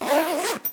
action_open_backpack_2.ogg